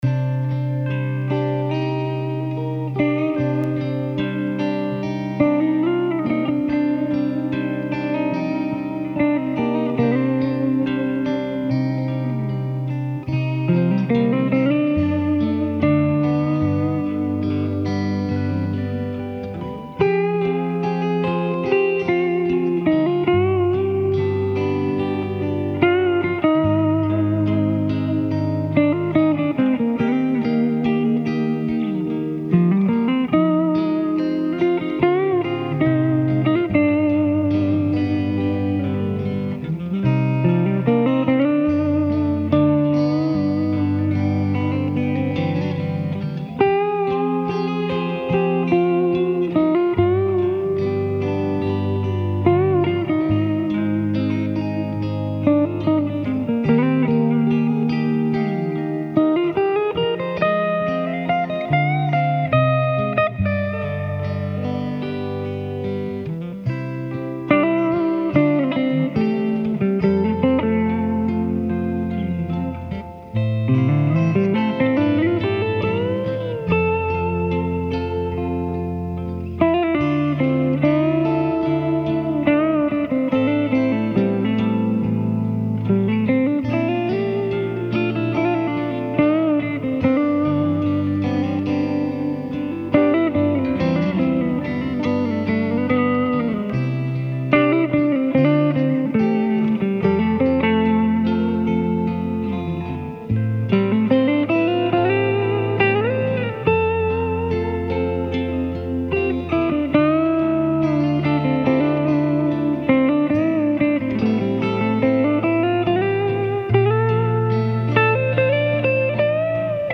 Clean or dirty, this guitar sounds amazing!